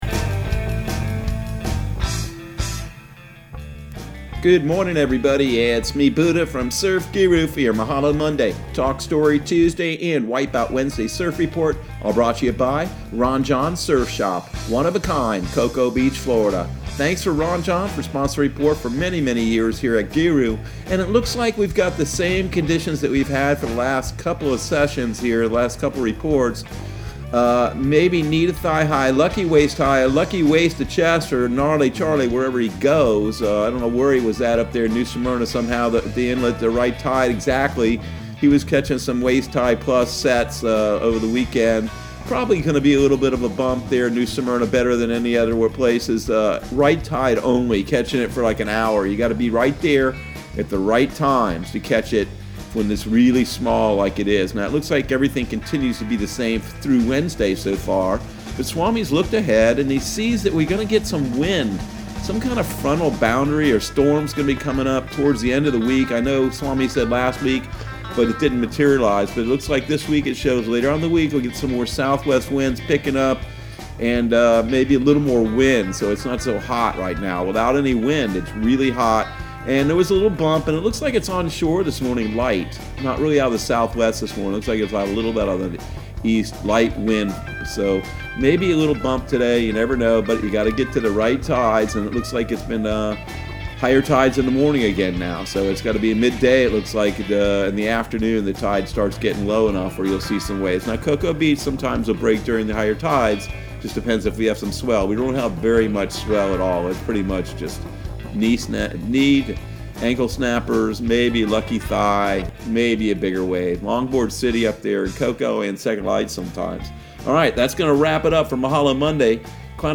Surf Guru Surf Report and Forecast 07/06/2020 Audio surf report and surf forecast on July 06 for Central Florida and the Southeast.